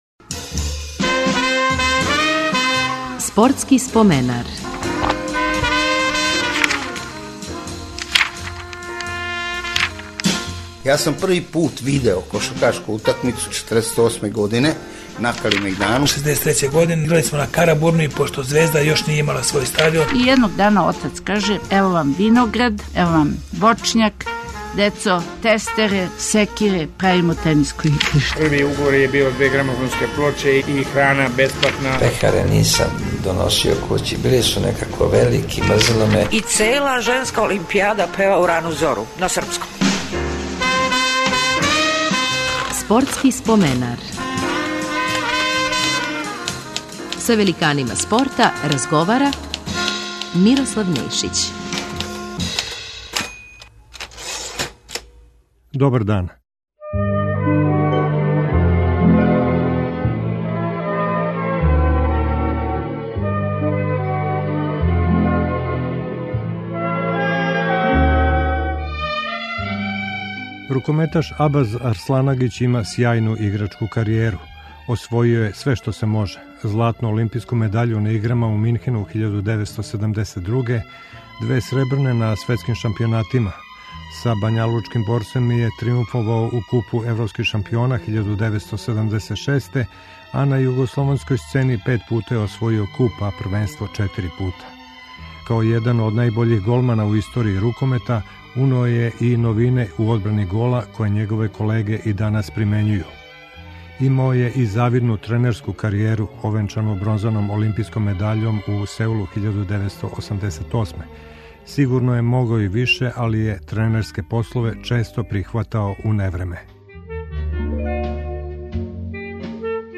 Биће коришћени и архивски тонски записи о освајању златне олимпијске медаље у Минхену, и сећању на напад палестинских терориста на израелске спортисте.